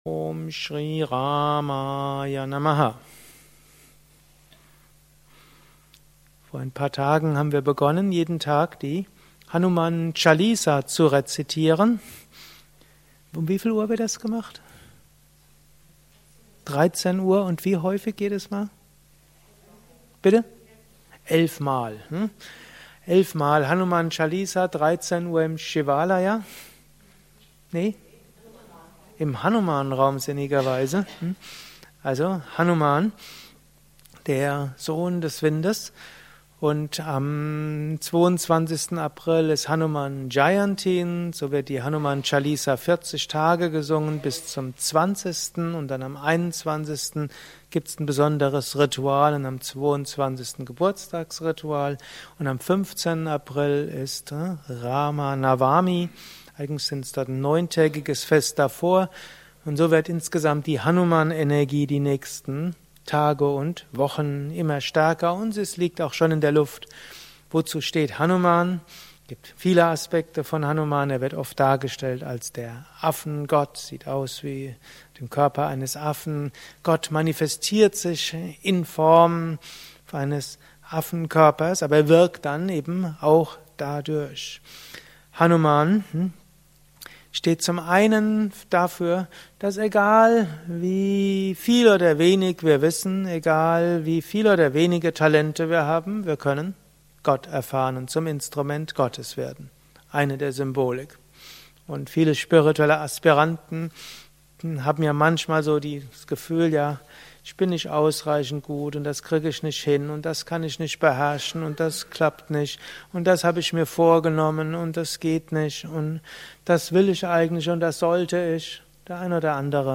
Anschluss nach einer Meditation im Haus Yoga Vidya Bad Meinberg.